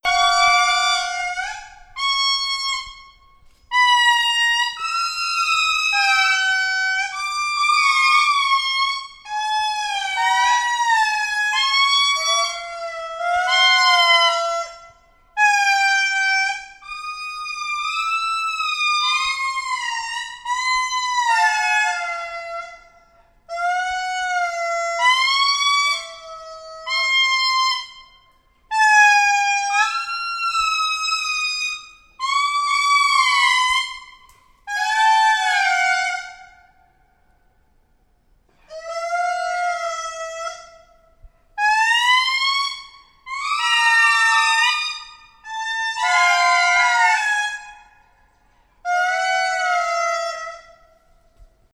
• lemur indri singing.wav
lemur_indri_singing_dTa.wav